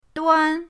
duan1.mp3